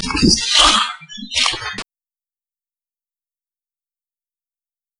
Alle Aufnahmen sind am Friedhof der Namenlosen entstanden und immer an der selben Stelle.